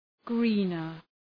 greener.mp3